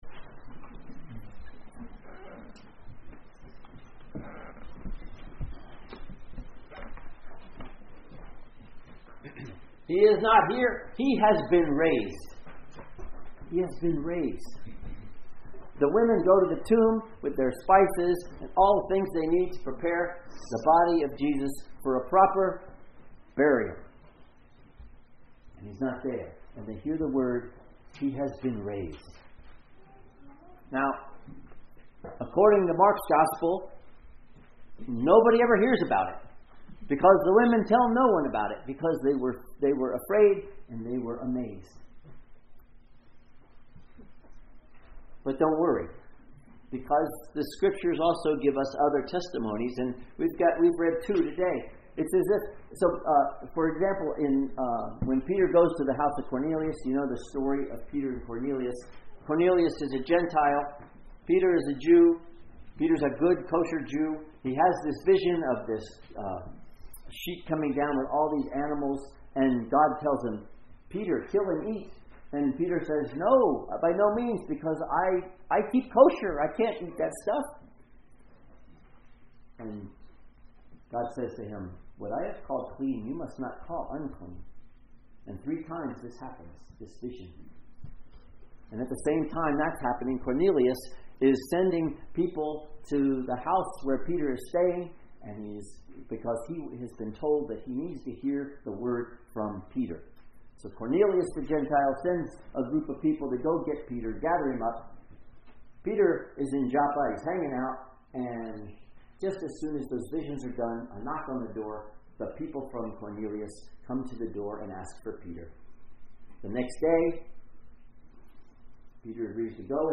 Sermons | Lake Chelan Lutheran Church
Easter Day Service